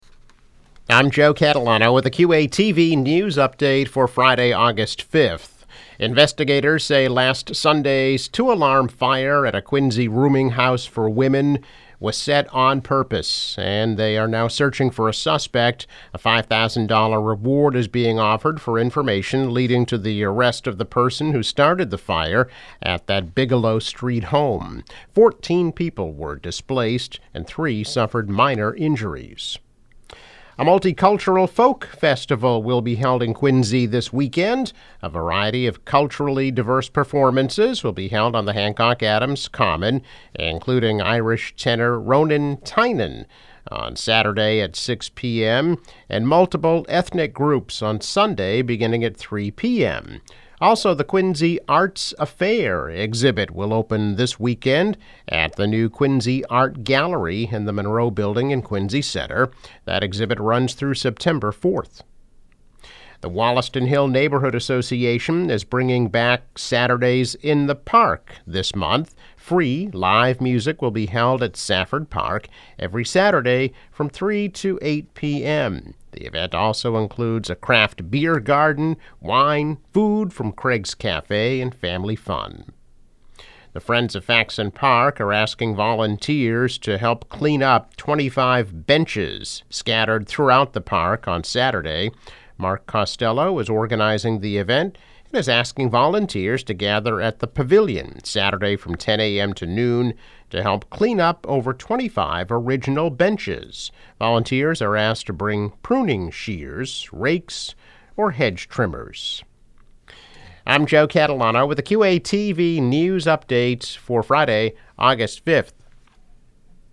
News Update - August 5, 2022